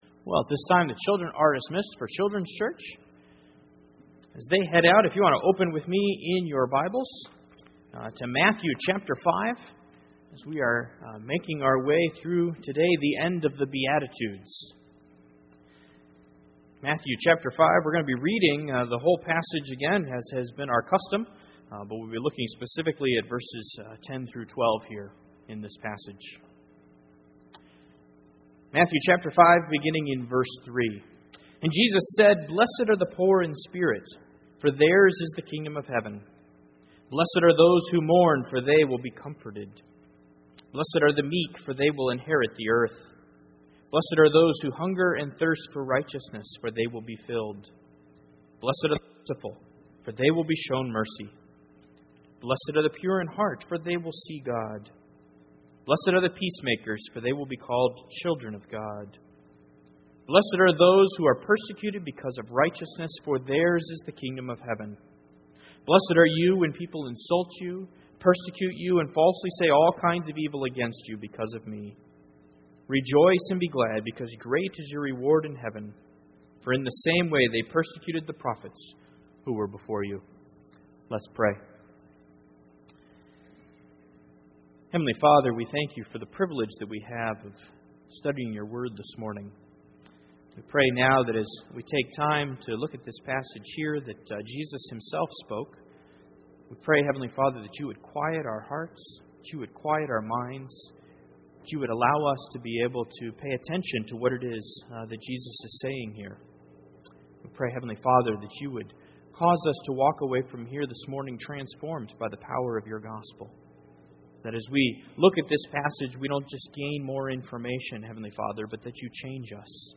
Sermon 2.mp3